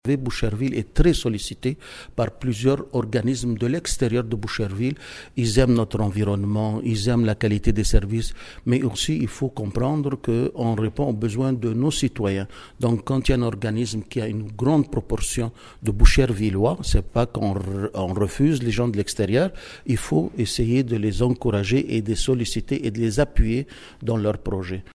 En entrevue avec le FM 103,3, le conseiller municipal Raouf Absi a expliqué que cet argent sert principalement à démarrer un projet.